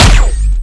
fire_pulse5.wav